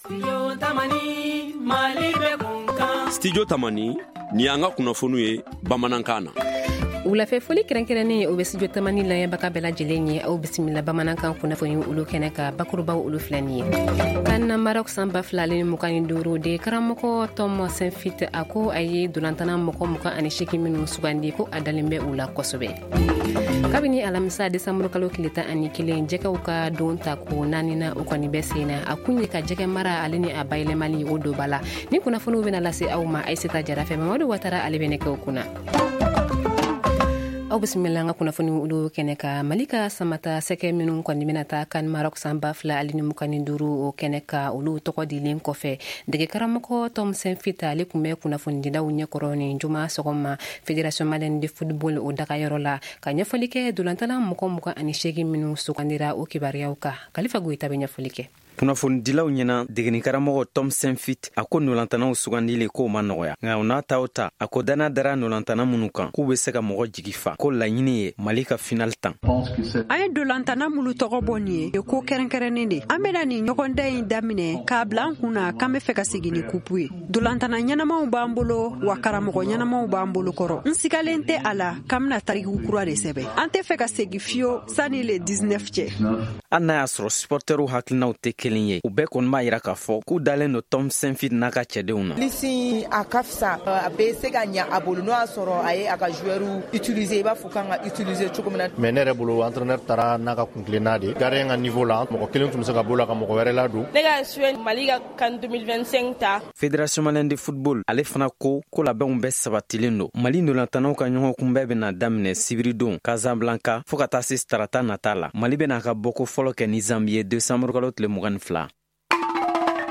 Le journal en Bambara du 12 décembre 2025